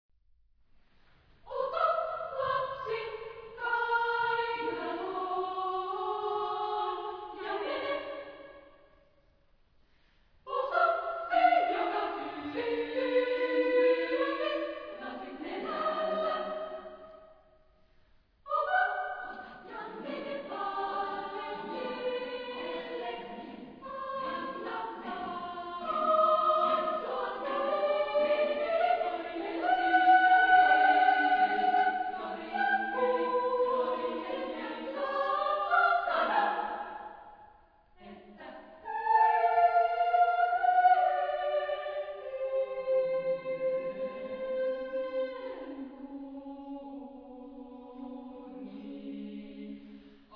Genre-Style-Form: Choir
Type of Choir: SSAA  (4 equal voices )
Soloist(s): Sopranos (2) / Alto (1)  (3 soloist(s))
Tonality: free tonality